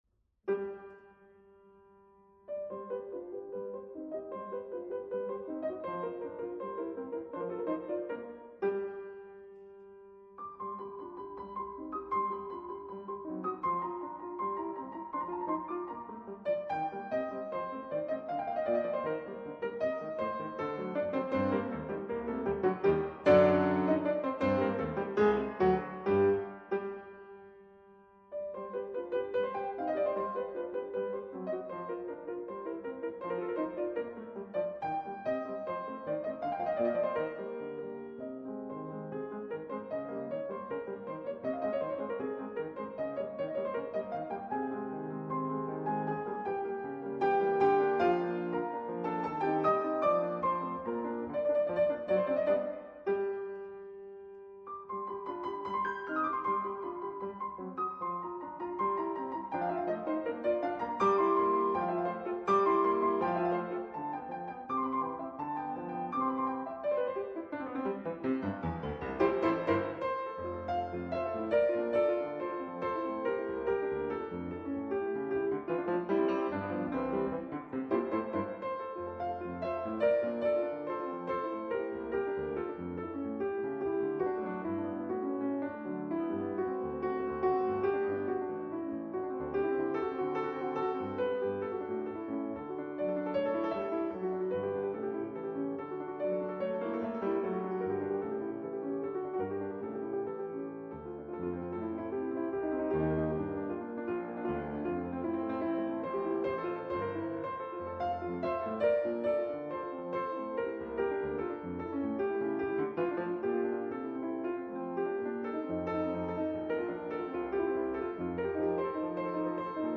Schubert: The Complete Finished Sonatas.